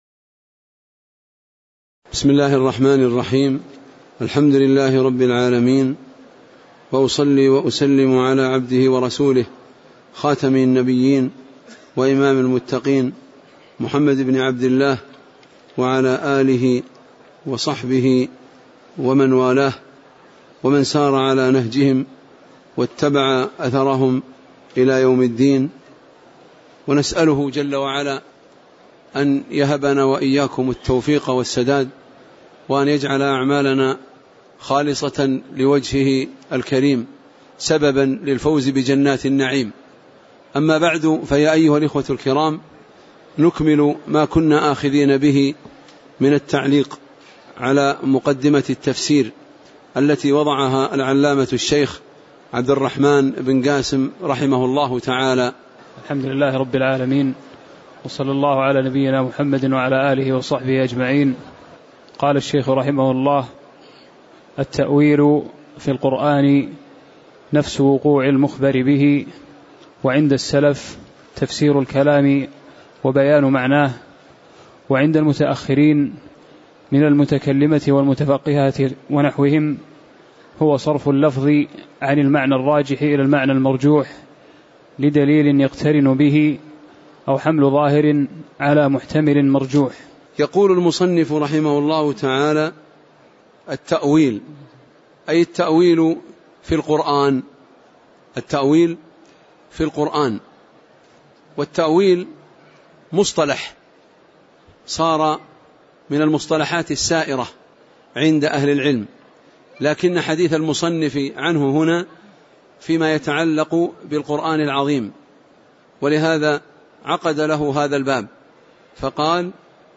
تاريخ النشر ١٣ شوال ١٤٣٩ هـ المكان: المسجد النبوي الشيخ